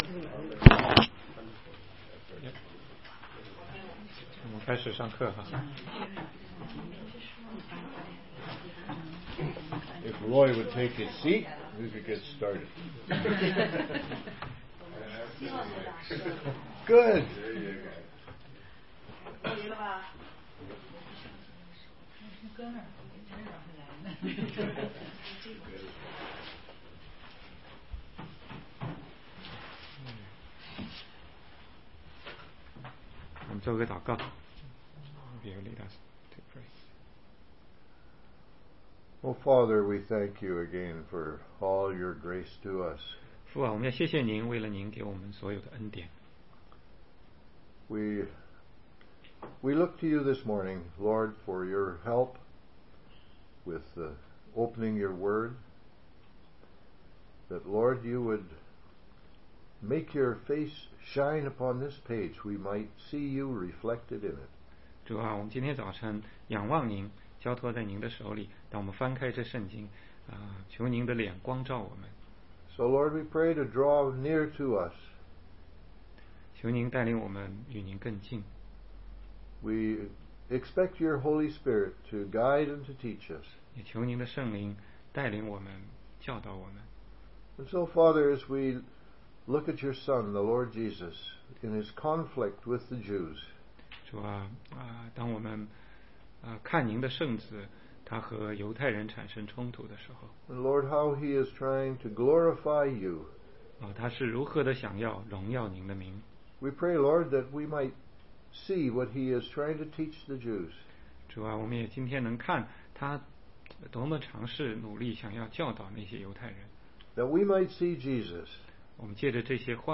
16街讲道录音 - 约翰福音8章52-59节